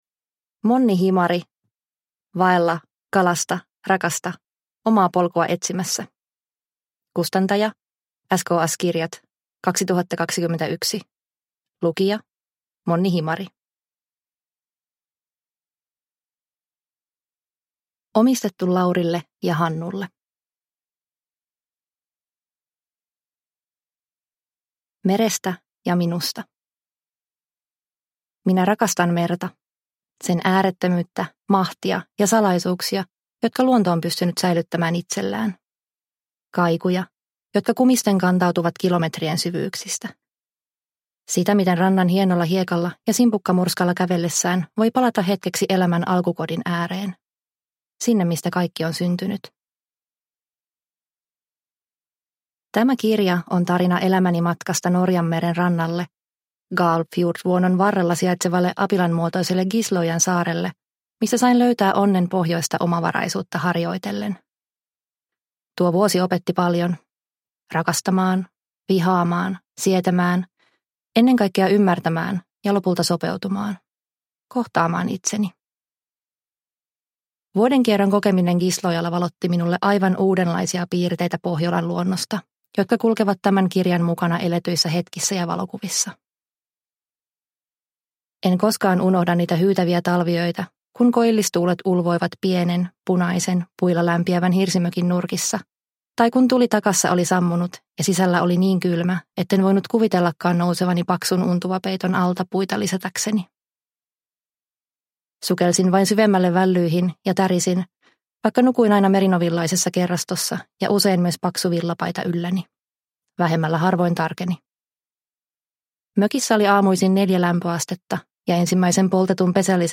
Vaella, kalasta, rakasta – Ljudbok – Laddas ner